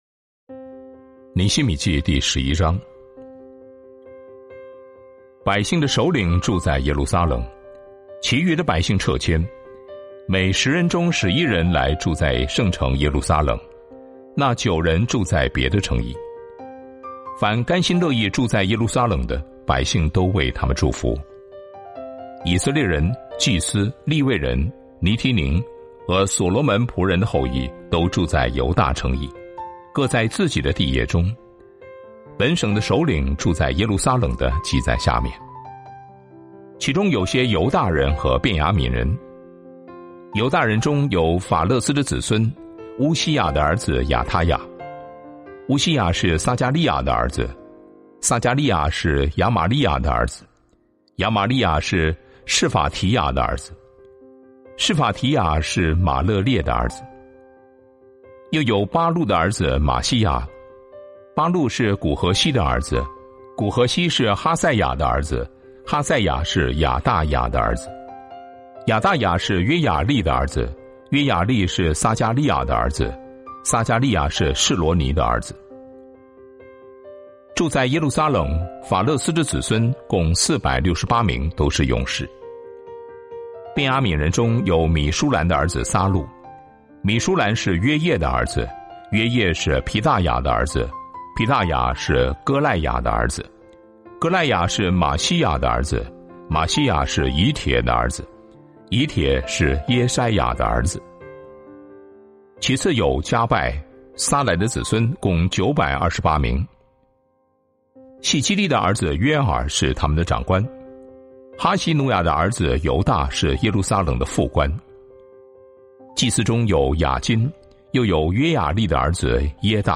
为领袖祷告 | Devotional | Thomson Road Baptist Church